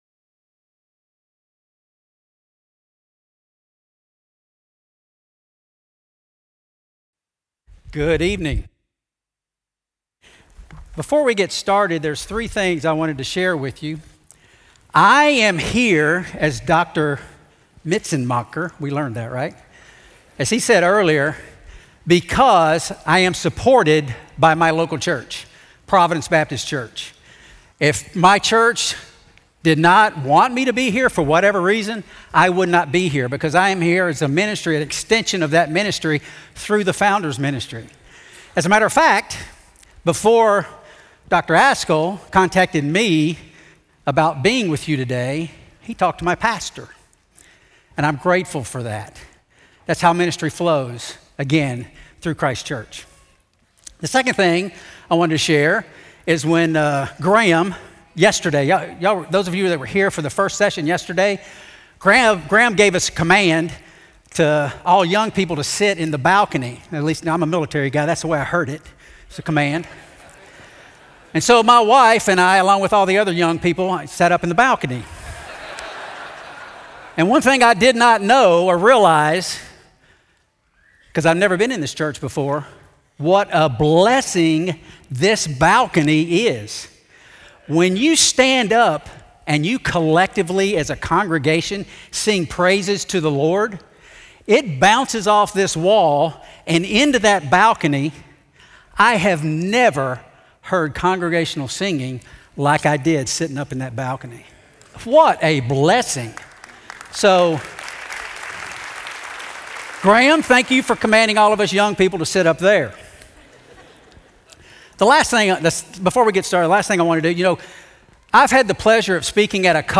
This presentation was given by Capt. Barry Wilmore on January 23, 2026 at the "Make Disciples" 2026 National Founders Conference in Fort Myers, Florida.